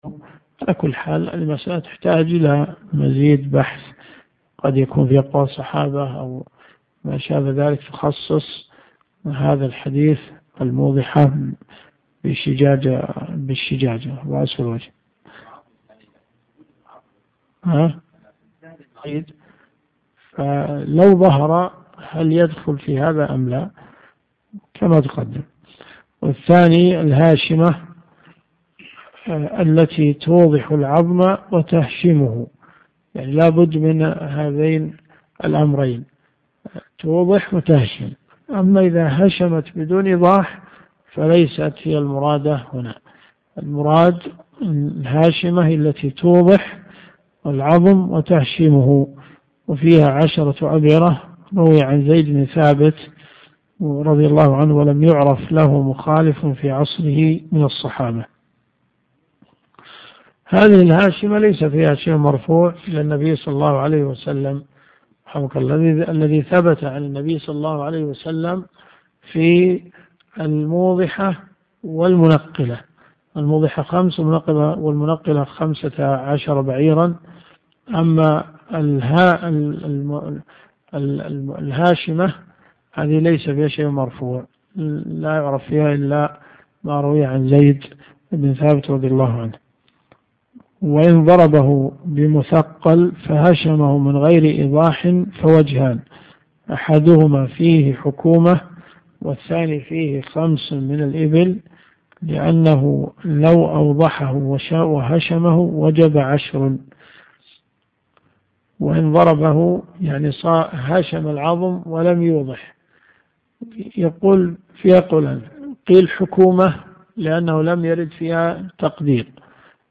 دروس صوتيه ومرئية تقام في جامع الحمدان بالرياض